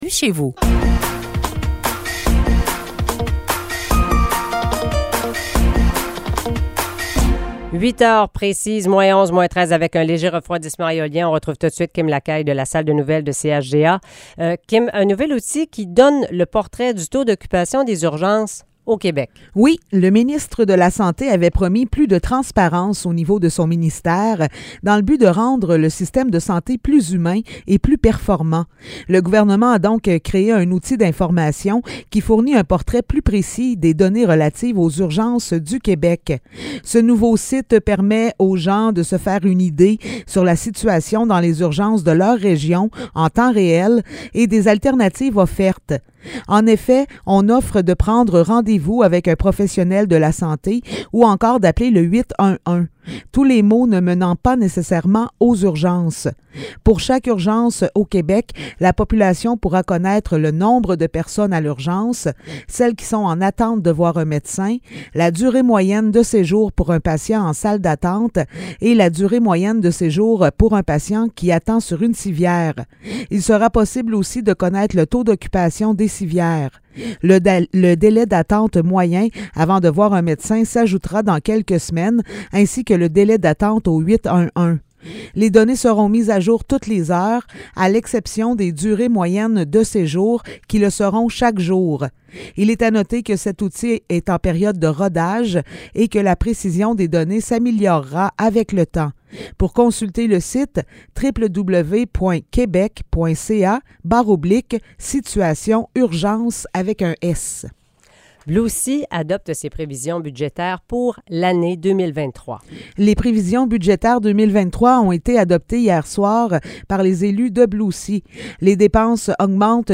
Nouvelles locales - 2 février 2023 - 8 h